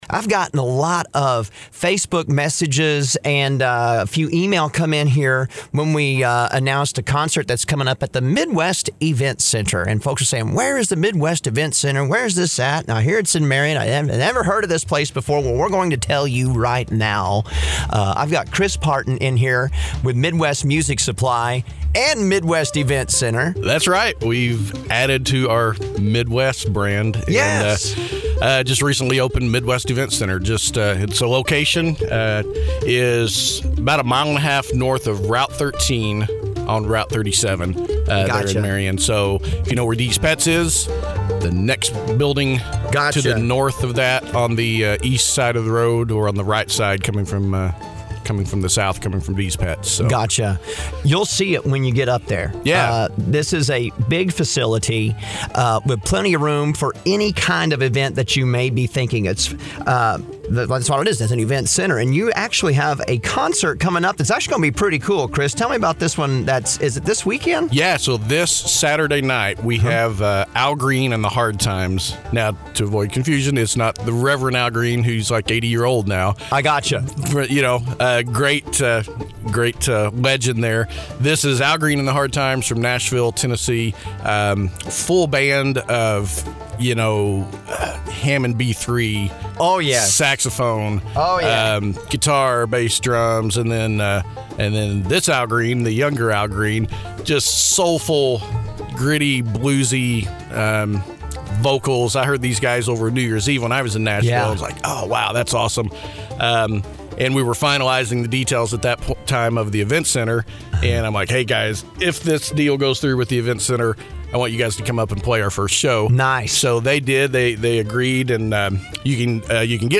AND, in case you missed it, here’s the interview from the show this morning!